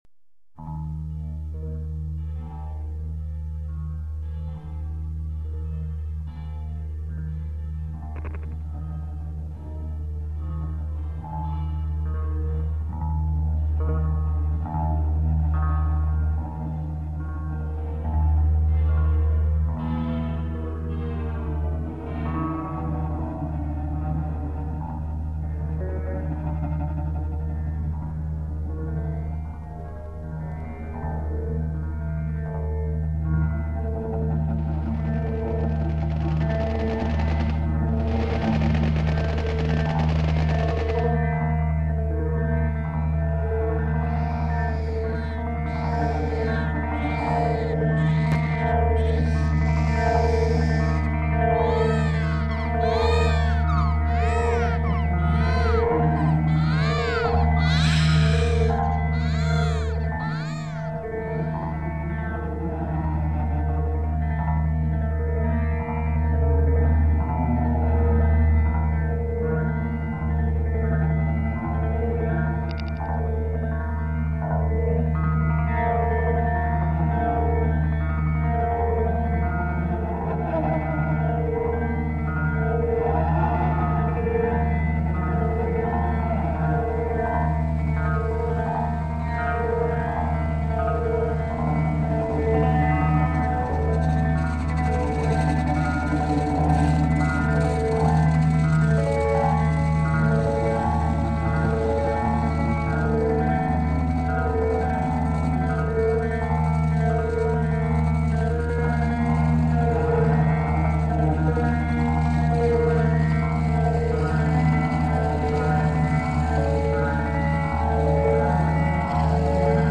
Folky trippy new age space,sometimes too flat and too sweet